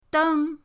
deng1.mp3